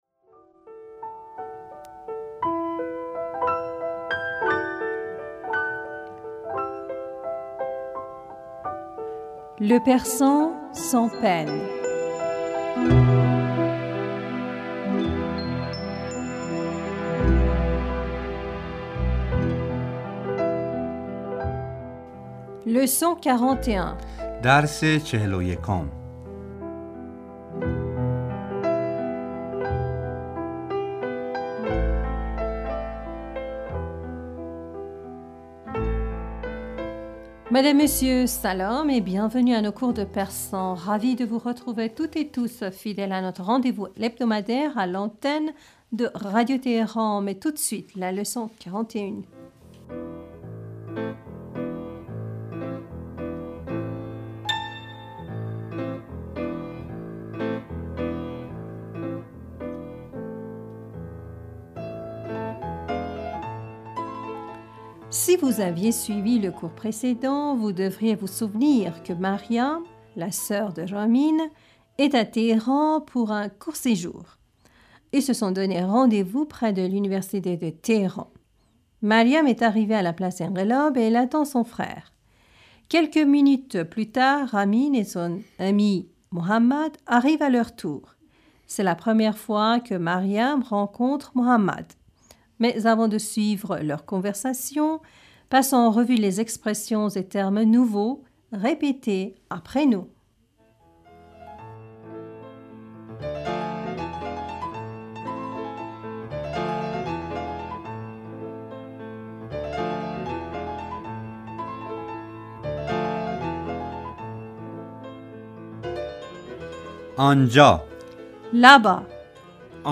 Répétez après nous.